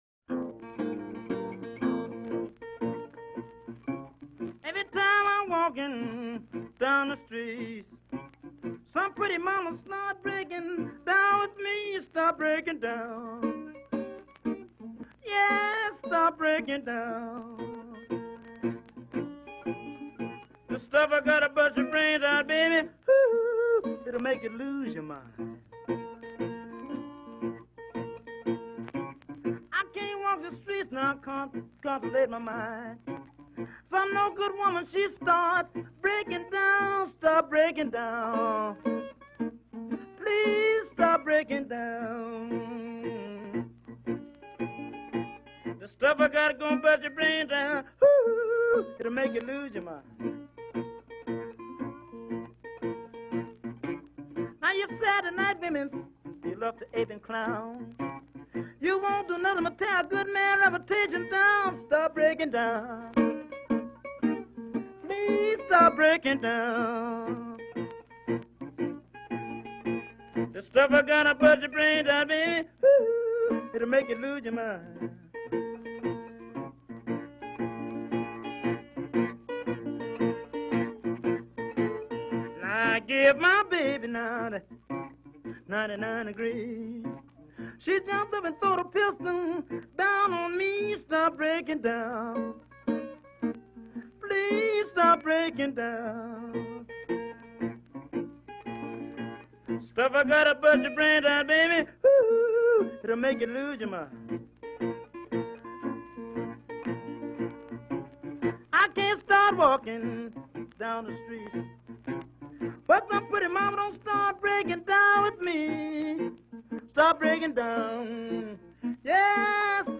all rudimentary strumming and picking